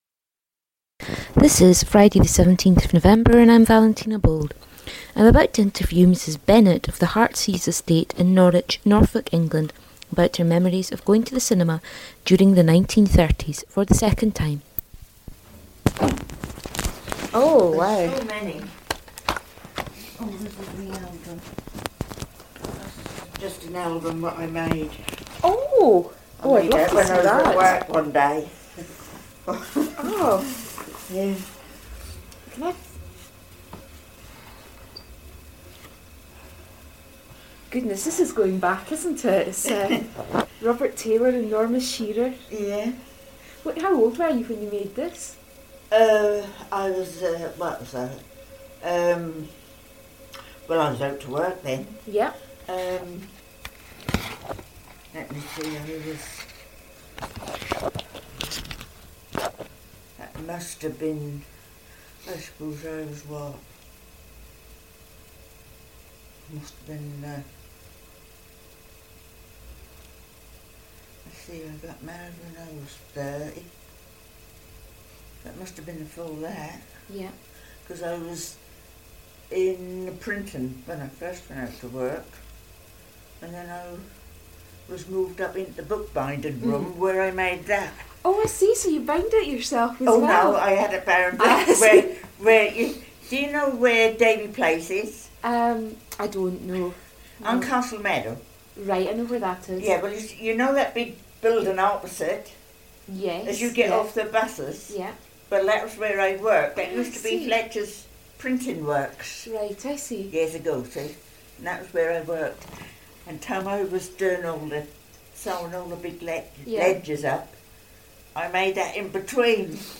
Sound Quality: Fair